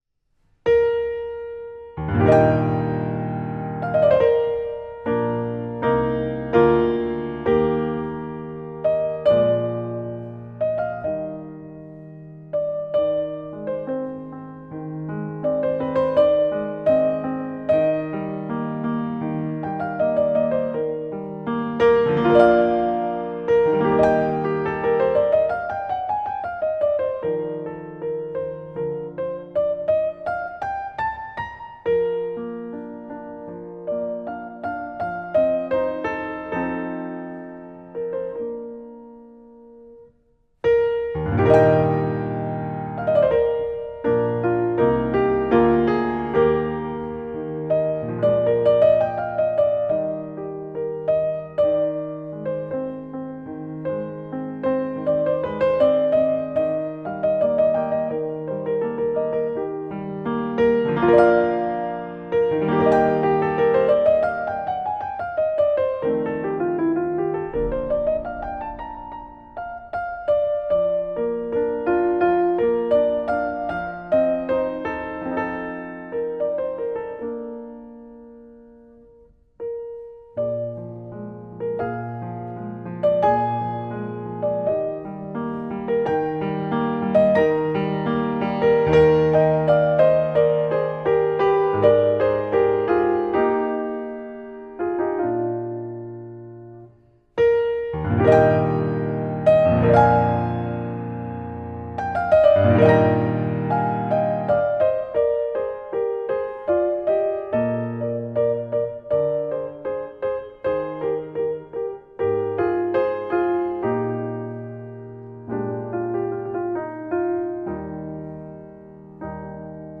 mp3Park, Maria Hester, A Waltz for piano, mm.1-19